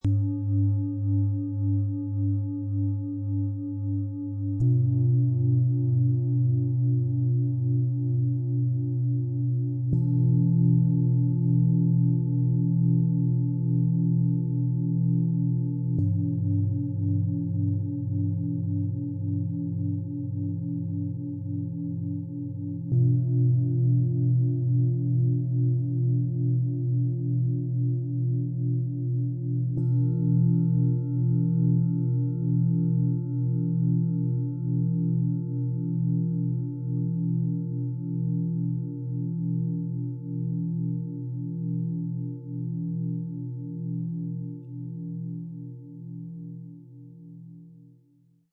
Sanfte Schwingung. Freundlicher Klang. Strukturiertes Ankommen.
Dieses sorgfältig abgestimmte Set besteht aus drei handgefertigten Klangschalen mit bewegenden Vibrationen und einem ausgleichenden Klangbild.
Durch ihren dünnwandigen Aufbau entfaltet diese Schale eine sanfte, vibrierende Tiefe, die besonders im Bauchbereich angenehm spürbar ist.
Auch bei dieser Schale sorgt die dünne Wandung für eine warm vibrierende Klangwelle, die Herz- und Brustraum berührt.
Ihr etwas dickwandigerer Aufbau bringt einen klaren, ruhigen Ton hervor.
Spüren Sie, wie sich feine Vibrationen und sanfte Klänge in Ihrem Raum entfalten.
Bengalen-Schale, matt